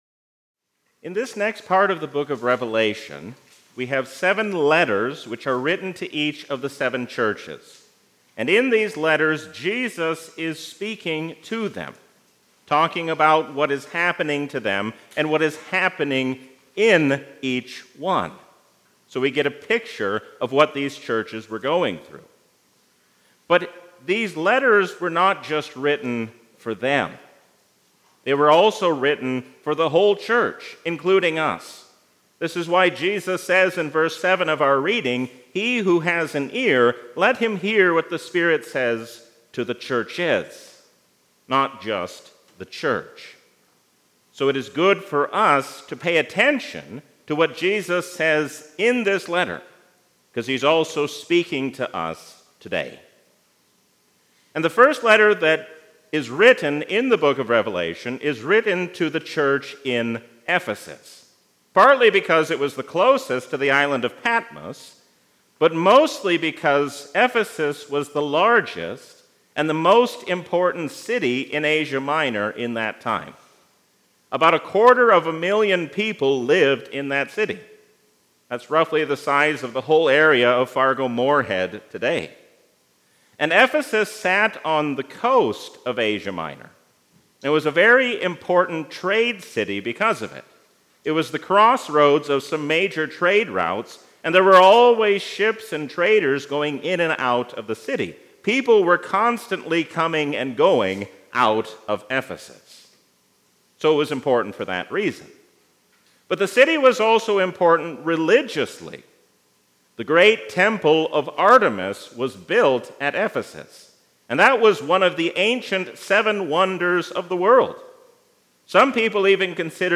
A sermon from the season "Easter 2023." Through truth without love is not good, love without truth is just as bad.